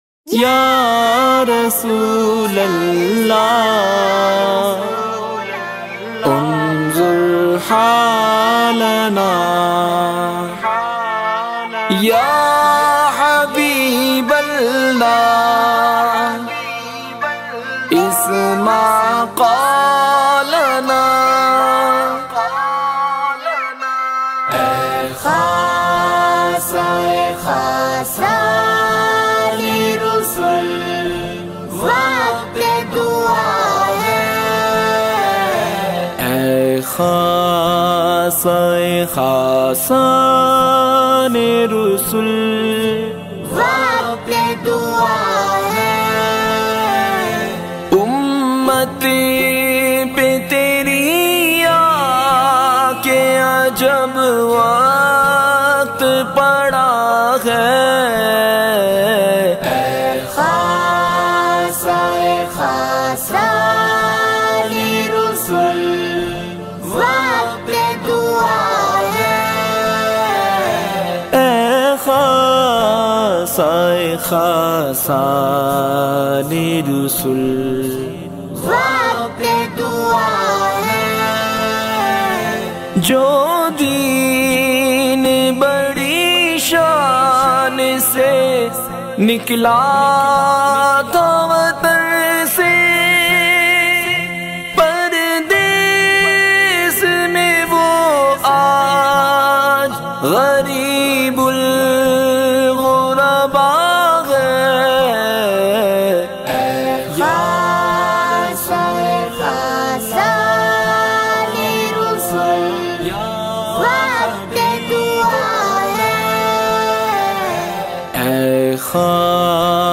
Explore and download a variety of Islamic MP3s.